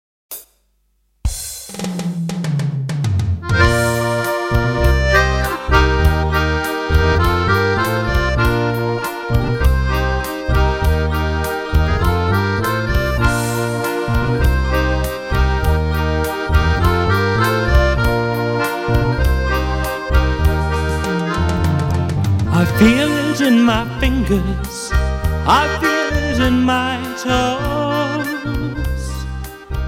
MIDI accordion with vocals